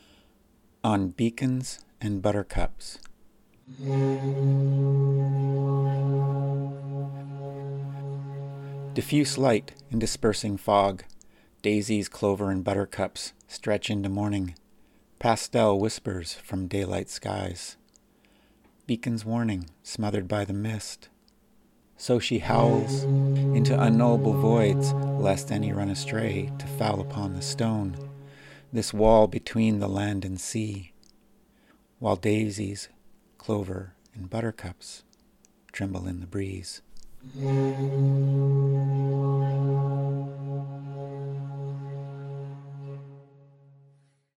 Reading of On Beacons and Buttercups with foghorn sound effect
There are two separate readings here, the second includes a recording of a foghorn, much like the one I heard the day I shot this photograph.